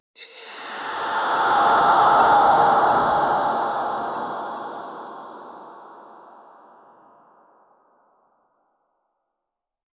ethereal whispers, ghostly summoning chant, growing in intensity, powerful magical energy crackle, forceful spiritual pressure, submissive ethereal sigh, climactic divine banishment, shockwave of light, fading ghostly moan 0:10
ethereal-whispers-ghostly-h6mtxu3s.wav